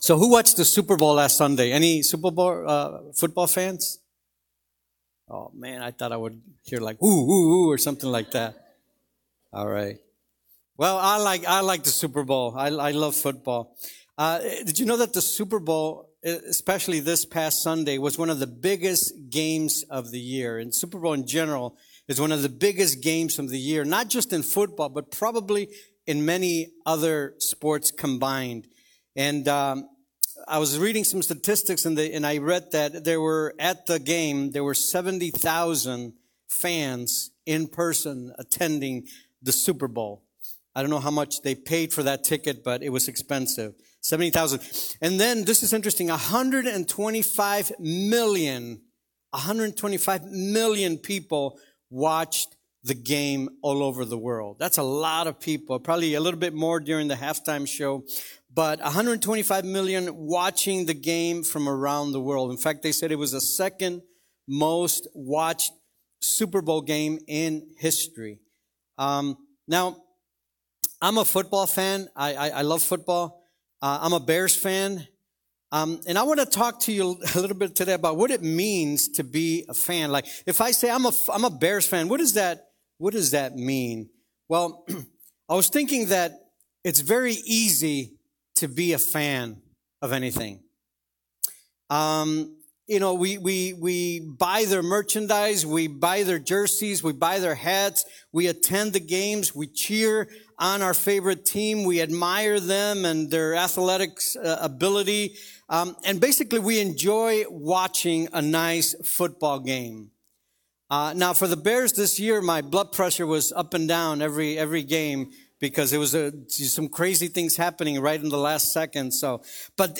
Sermons and Audio Messages from New Life Community Church